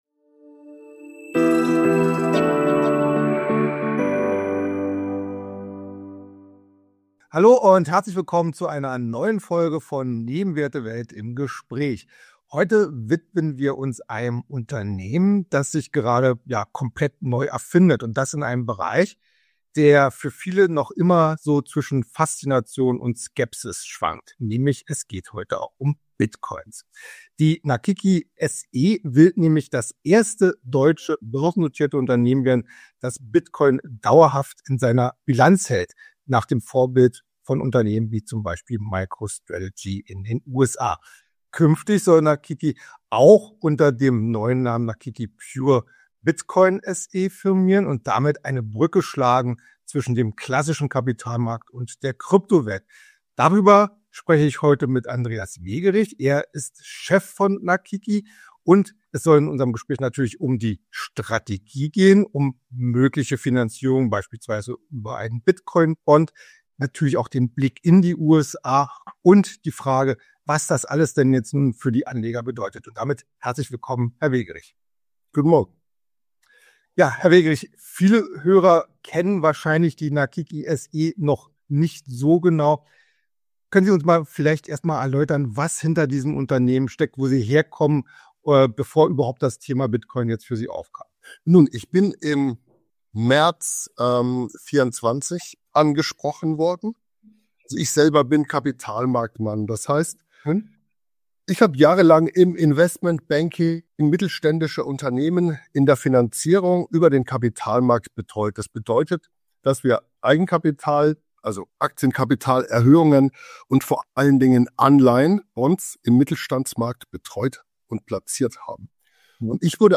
Ein spannendes Gespräch über einen Ansatz, der in Deutschland Pioniercharakter hat.